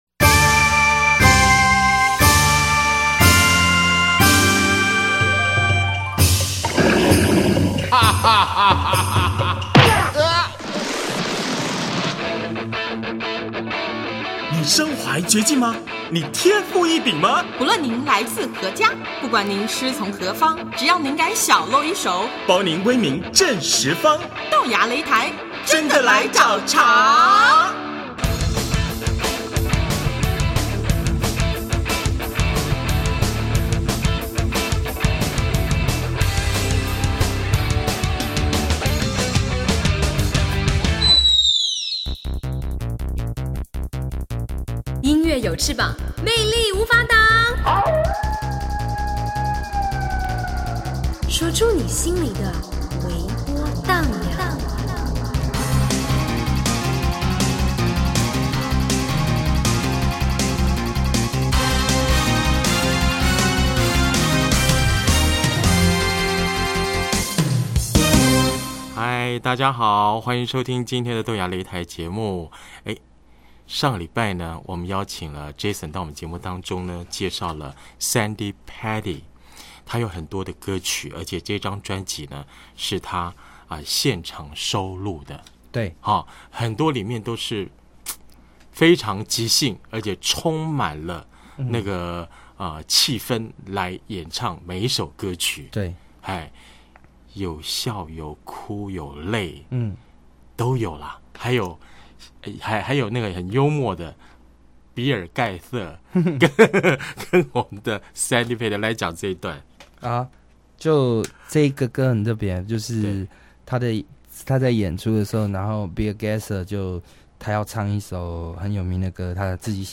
每首歌都是温和柔美、抚慰人心的曲风